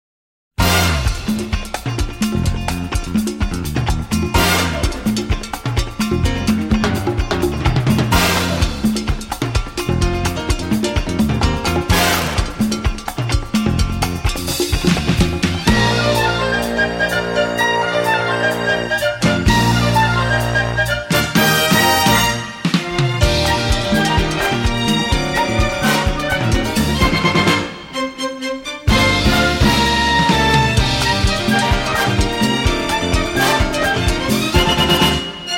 纯音乐 - 欢快开场音乐